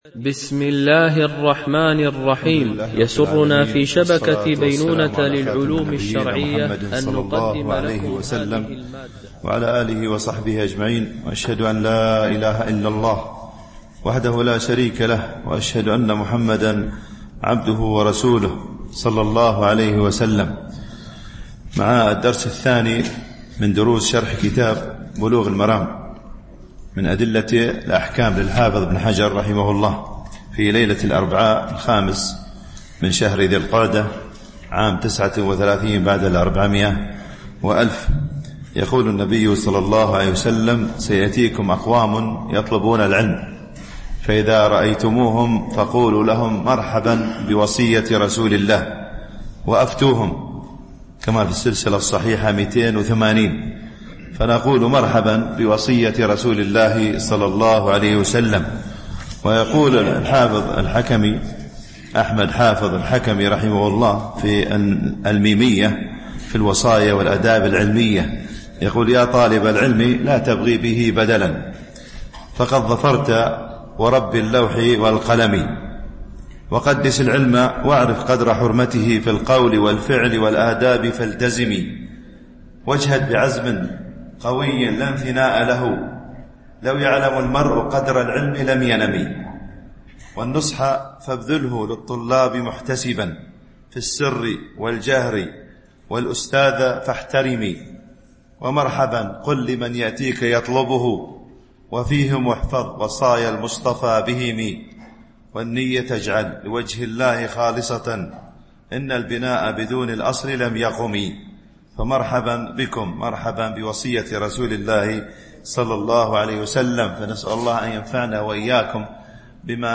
شرح بلوغ المرام من أدلة الأحكام - الدرس 2 (كتاب الطهارة، باب المياه، الحديث. 1- 5)